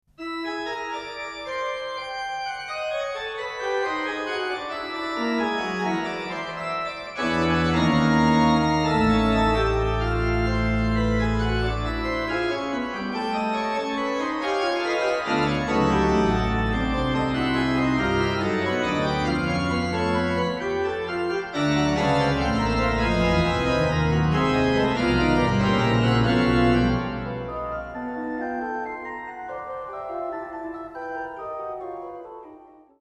Besetzung Orgel